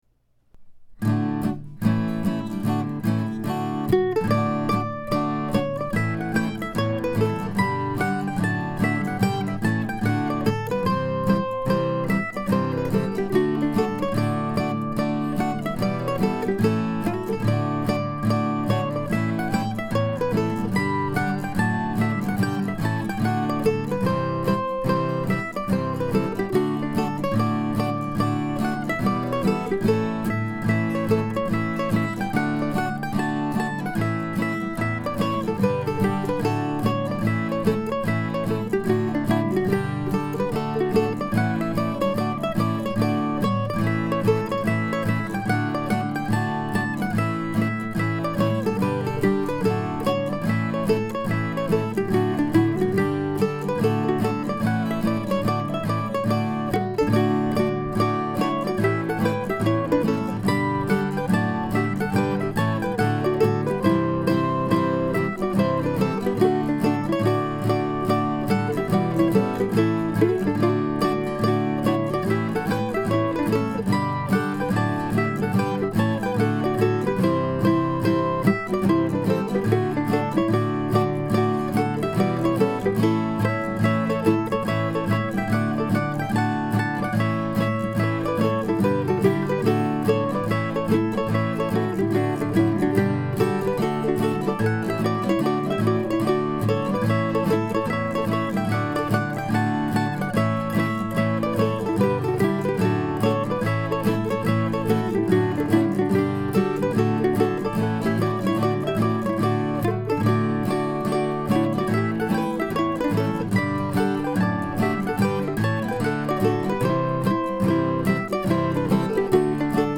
Written on a cold day near the end of 2013, this schottische-like tune led me to a memory of my father.
Recorded today, a cold, rainy day in April with a Martin guitar and a Lafferty mandolin.